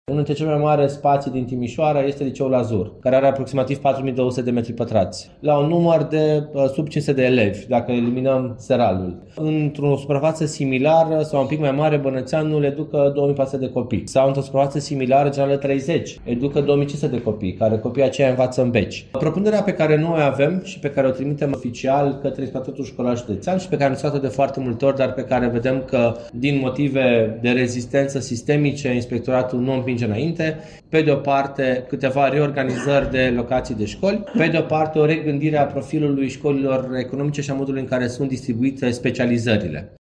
Viceprimarul Timișoarei, Ruben Lațcău, spune că există școli aproape goale, în timp ce altele sunt supraaglomerate, iar această situație nu mai poate continua.